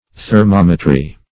Thermometry \Ther*mom"e*try\, n.